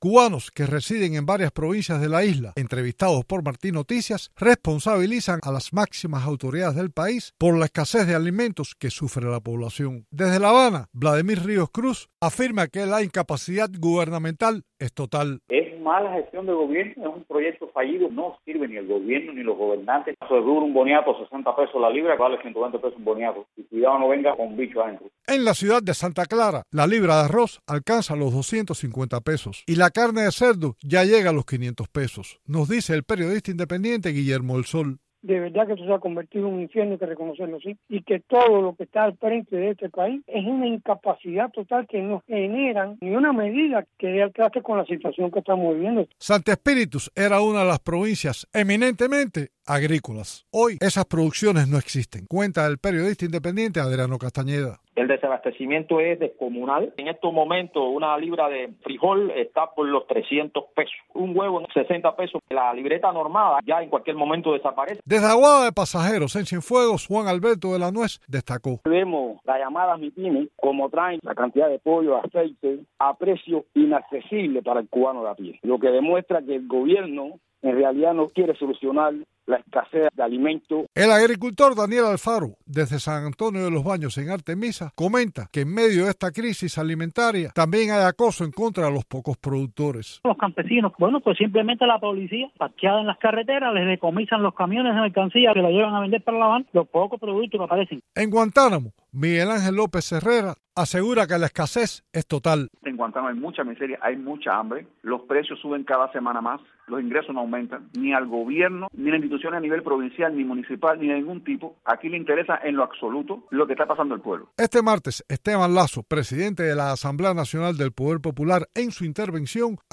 Cubanos residentes en varias provincias de la isla entrevistados por Martí Noticias responsabilizaron a las máximas autoridades del país por la grave escasez de alimentos que sufre la población.